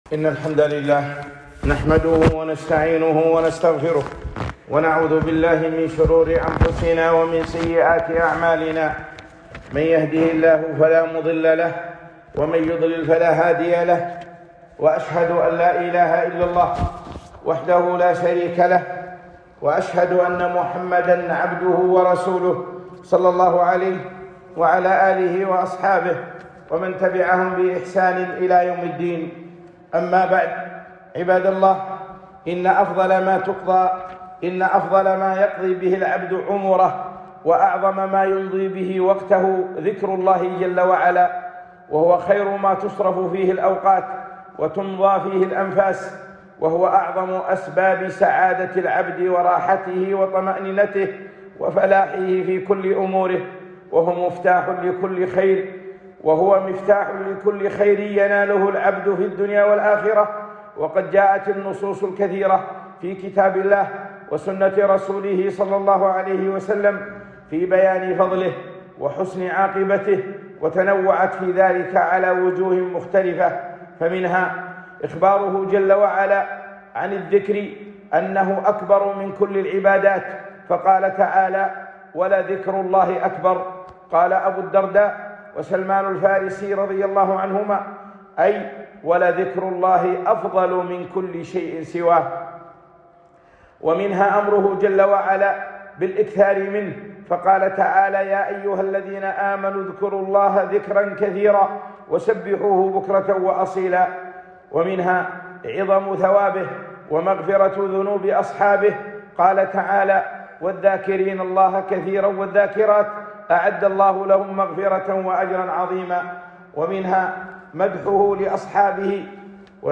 خطبة - فضل الذكر